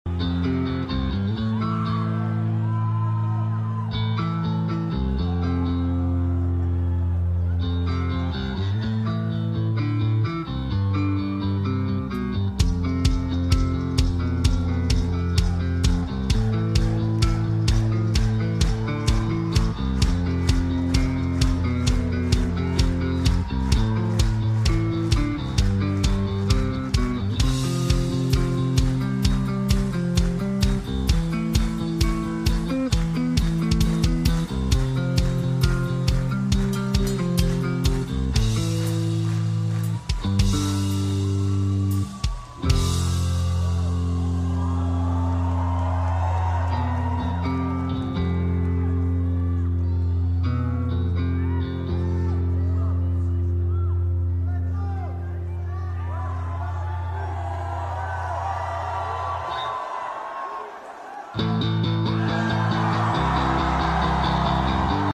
Solo De Baixo Introdução Da Sound Effects Free Download